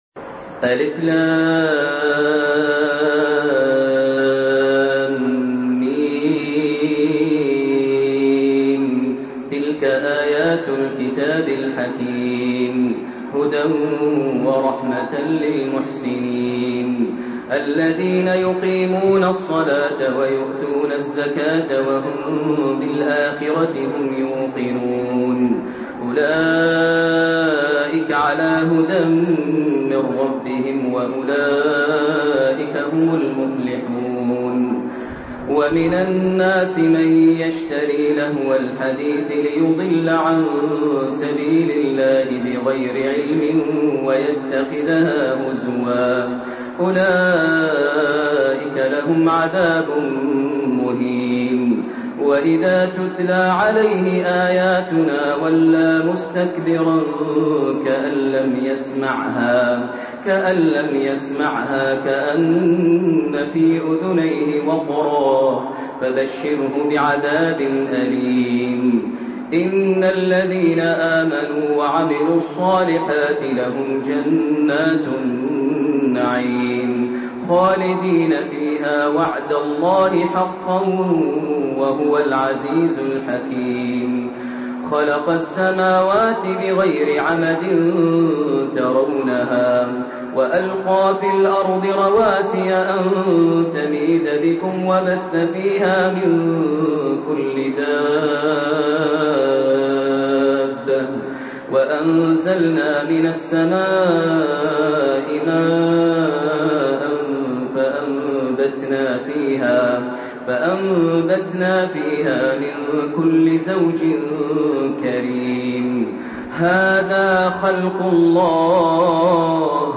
سورة لقمان و سورة السجدة وسورة الأحزاب 1- 26 > تراويح ١٤٣٢ > التراويح - تلاوات ماهر المعيقلي